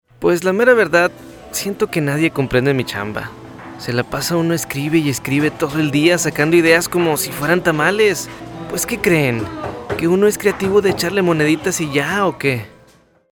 Spanish voice Natural Conversational
Sprechprobe: Sonstiges (Muttersprache):